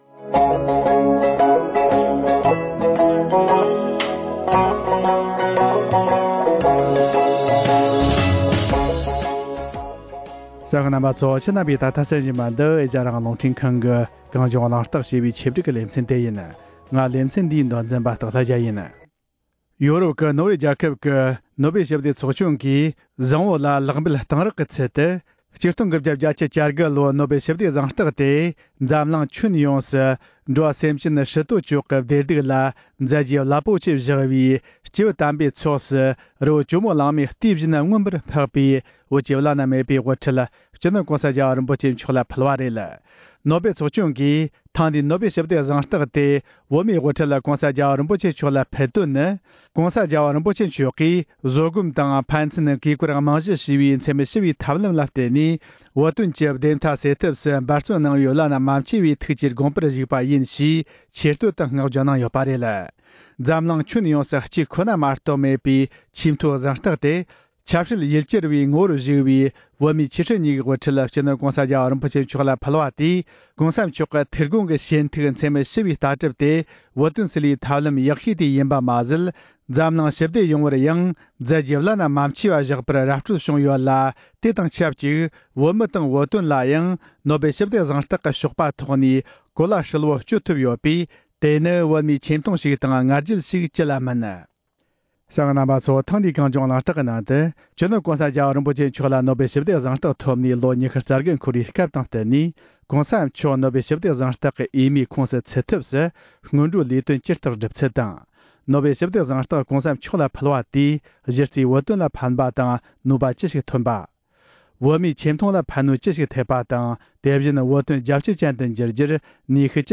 ཐེངས་འདིའི་གངས་ལྗོངས་གླེང་རྟགས་ནང་དུ། ༧སྤྱི་ནོར༧གོང་ས་རྒྱལ་བ་རིན་པོ་ཆེ་མཆོག་ལ་ནོ་བྷེལ་ཞི་བདེ་གཟེངས་རྟགས་ཀྱི་འོས་མིའི་ཁོངས་སུ་ཚུད་ཐབས་སུ་སྔོན་འགྲོའི་ལས་དོན་ཇི་ལྟར་སྒྲུབ་ཚུལ་དང་། ནོ་བྷེལ་ཞི་བདེ་གཟེངས་རྟགས་༧གོང་ས་མཆོག་ལ་ཕུལ་བ་དེས་གཞི་རྩའི་བོད་དོན་ལ་ཕན་པ་དང་ནུས་པ་ཅི་ཞིག་ཐོན་པ། བོད་མིའི་ཆེ་མཐོང་ལ་ཕན་ནུས་ཅི་ཞིག་ཐེབས་པ་དང་། དེ་བཞིན་བོད་དོན་རྒྱལ་སྤྱི་ཅན་དུ་འགྱུར་རྒྱུར་ཕན་ནུས་ཅི་ཞིག་ཐོན་པ་ལ་སོགས་པའི་ཐད་གླེང་མོལ་བྱེད་རྒྱུ་ཡིན། ཞུ་སྐུ་མགྲོན་ནི།བཀའ་བློན་ཁྲི་ཟུར་སྐུ་ངོ་བཀྲས་མཐོང་བསྟན་འཛིན་རྣམ་རྒྱལ།